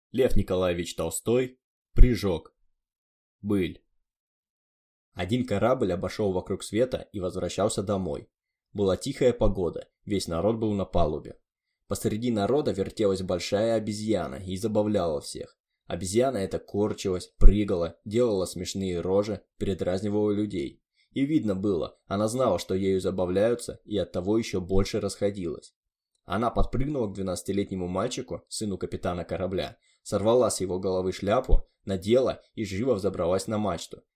Аудиокнига Прыжок | Библиотека аудиокниг